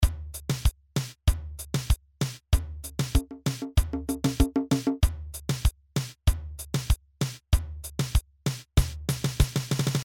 描述：Electro loop 96
标签： 96 bpm Electronic Loops Drum Loops 1.68 MB wav Key : Unknown
声道立体声